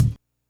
kick02.wav